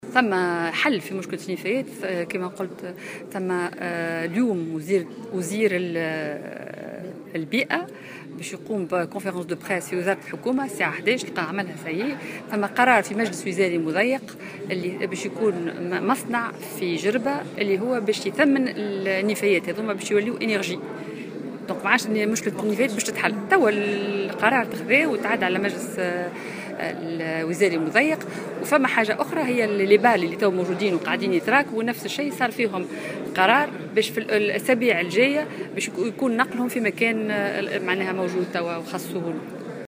وأفادت في تصريح لـ "الجوهرة أف أم" اليوم على هامش ملتقى انعقد في جربة، أن هذا القرار تم اتخاذه مؤخرا على مستوى حكومي وسيتم تنفيذه قريبا.